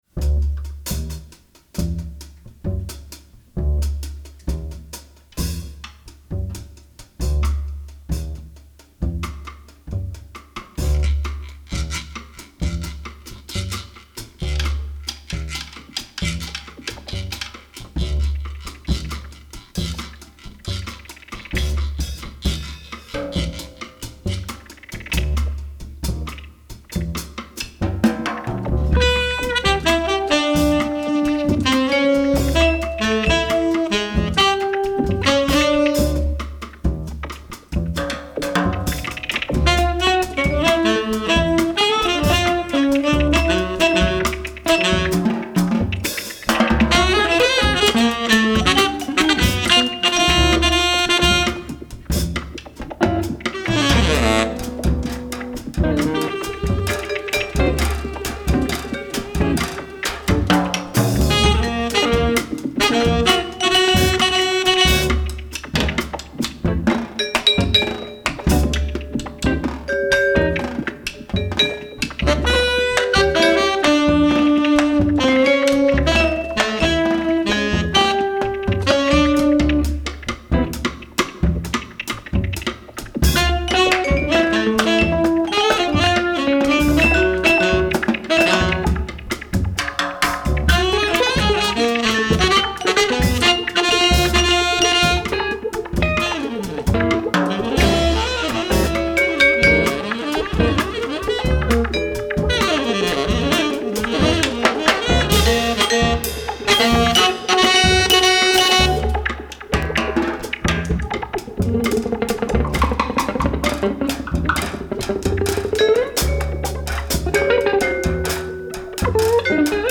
tenor saxophone
electric guitar
acoustic bass
drums
percussion
electronics